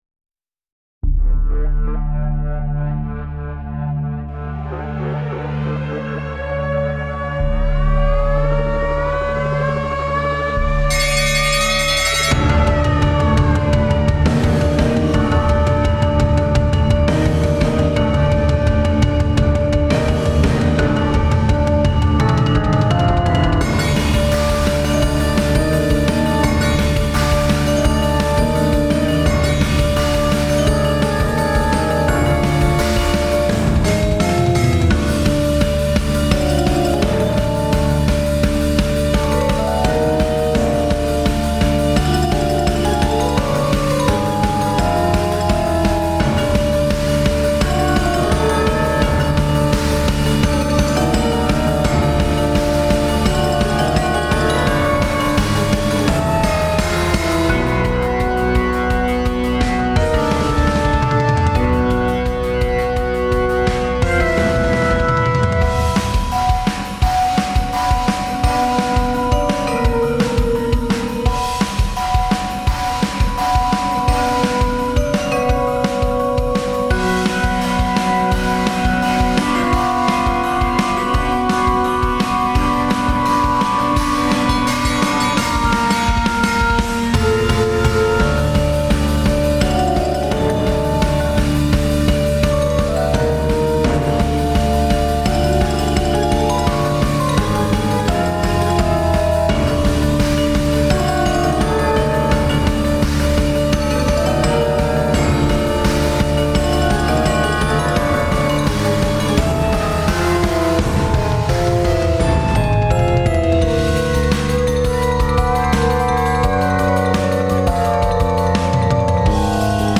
Genre: Percussion Ensemble
Percussion 4: Vibraphone
Electric Bass: May substitute Synthesizer